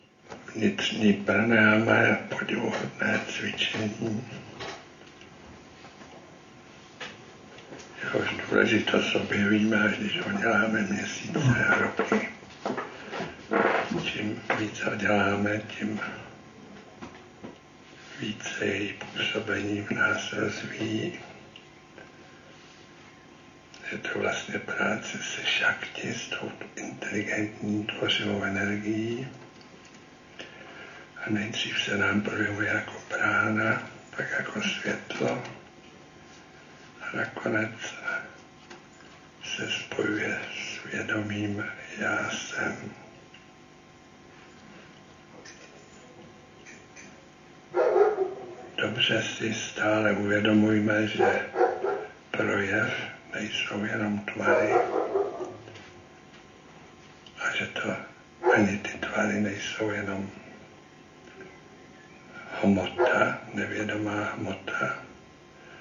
Jednota projevu a neprojevu neboli jednota vědomí a jeho tvořivé moci. Přímý záznam promluvy ze semináře v Kostolanech a následného cvičení vnitřní pránájámy v červnu 2006. Záznam nebyl režírován ani jinak upravován.
Proto jsou na něm slyšitelné i zvuky z okolí.
Nejedná se o studiovou nahrávku.